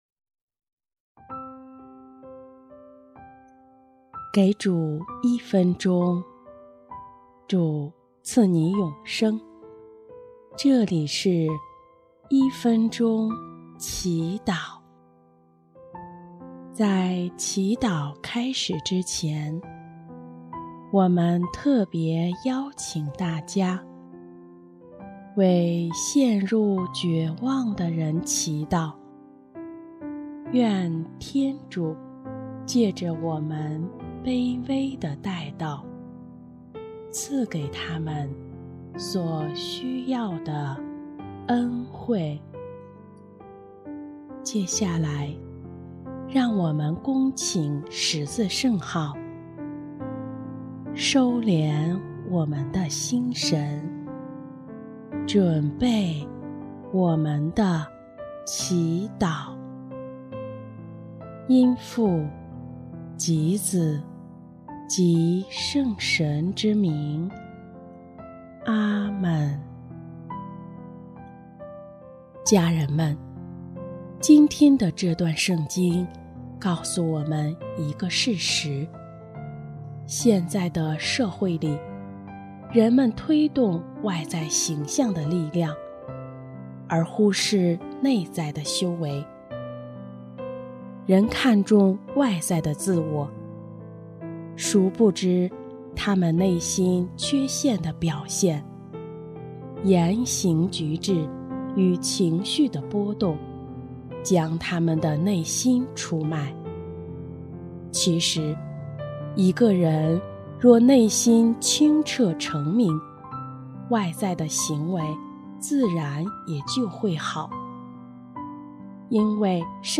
音乐： 第三届华语圣歌大赛参赛歌曲《珍惜》（为陷入绝望的人祈祷）